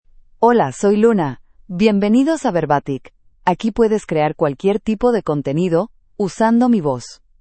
Luna — Female Spanish (United States) AI Voice | TTS, Voice Cloning & Video | Verbatik AI
Luna is a female AI voice for Spanish (United States).
Voice: LunaGender: FemaleLanguage: Spanish (United States)ID: luna-es-us
Voice sample